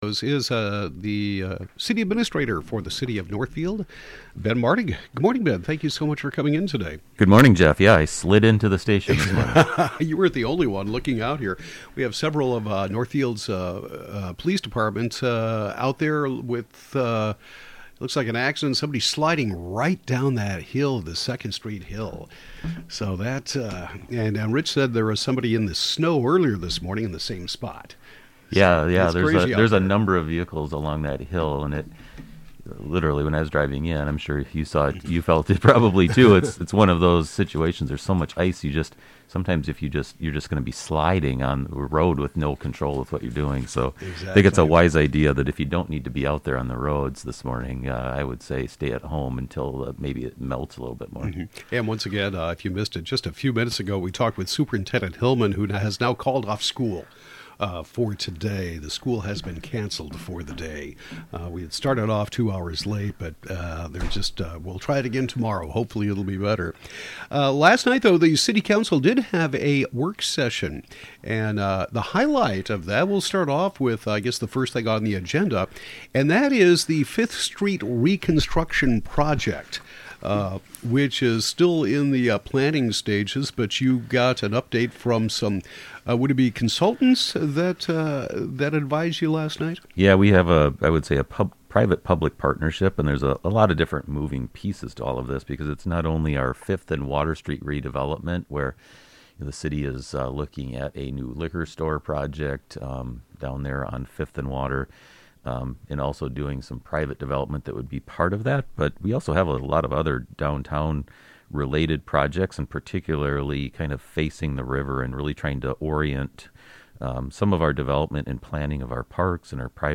City Administrator Ben Martig gives an update from the January 10th City Council work session, and in particular the long term plans and vision for redeveloping the 5th & Water Street area and the full Northfield Riverfront.